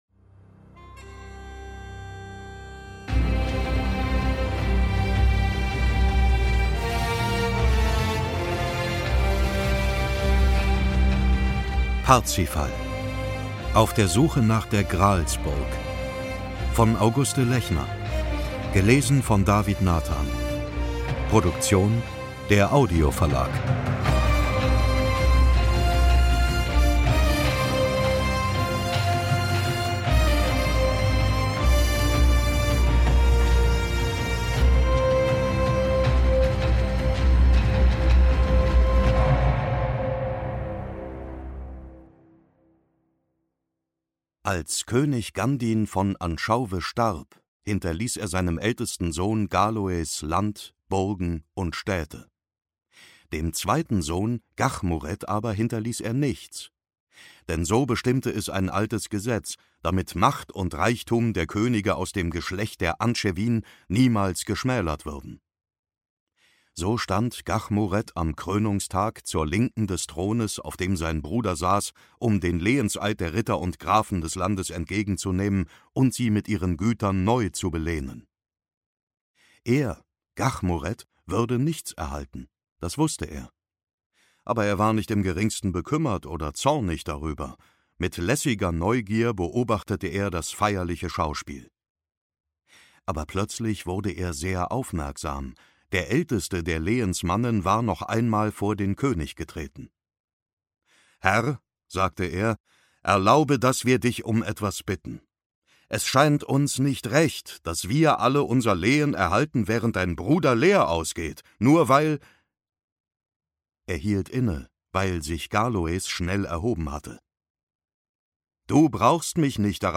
Parzival – Auf der Suche nach der Gralsburg Ungekürzte Lesung mit Musik
David Nathan (Sprecher)